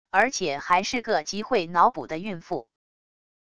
而且还是个极会脑补的孕妇wav音频生成系统WAV Audio Player